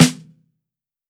• Round Snare Drum Sound B Key 116.wav
Royality free snare sound tuned to the B note.
round-snare-drum-sound-b-key-116-gdH.wav